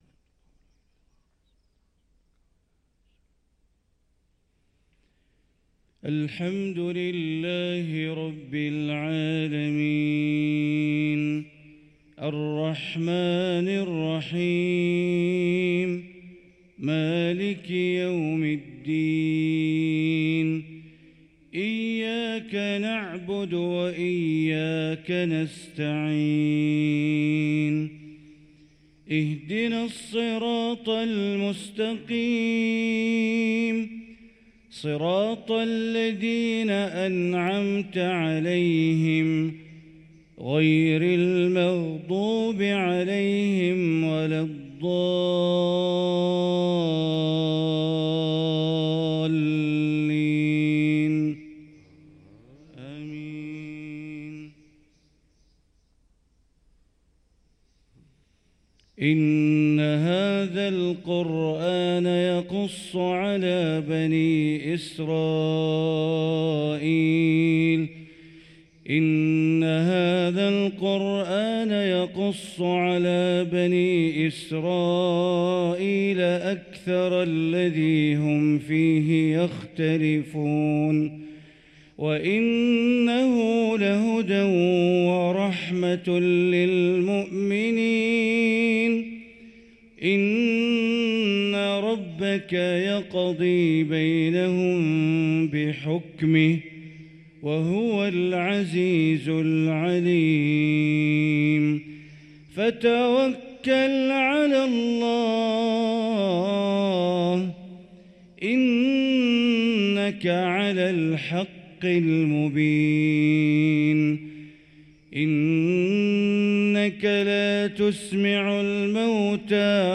صلاة الفجر للقارئ بندر بليلة 27 جمادي الآخر 1445 هـ
تِلَاوَات الْحَرَمَيْن .